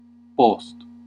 Ääntäminen
US : IPA : /ˈæf.tɚ/ UK : IPA : /ˈɑːftə(ɹ)/